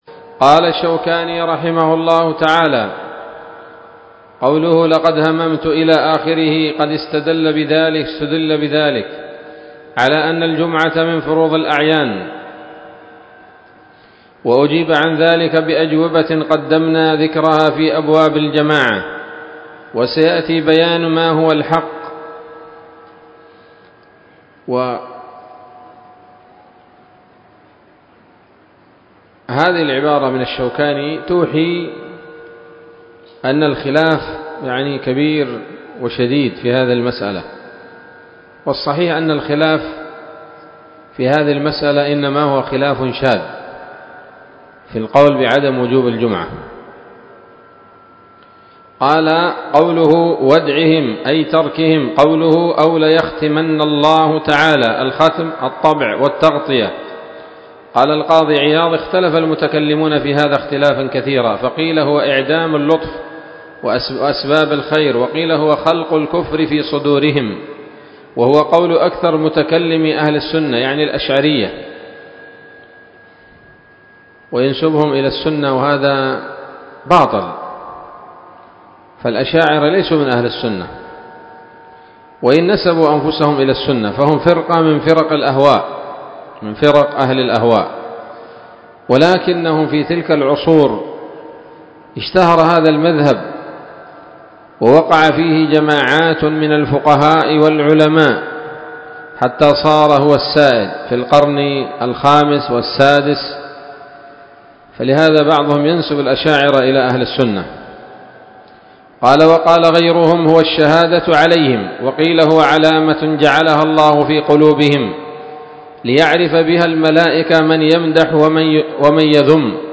الدرس الثاني من ‌‌‌‌أَبْوَاب الجمعة من نيل الأوطار